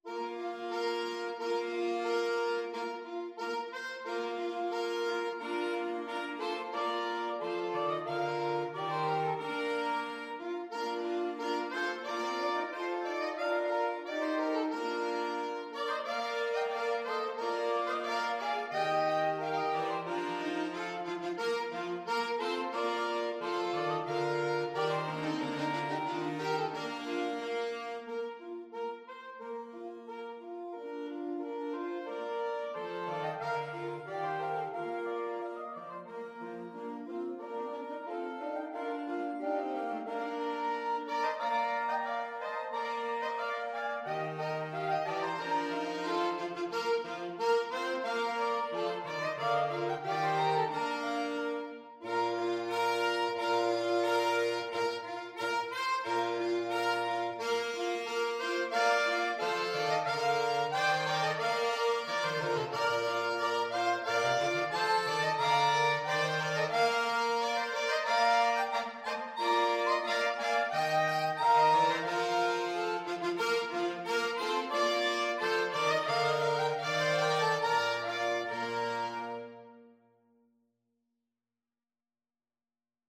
March ( = c. 90)
2/2 (View more 2/2 Music)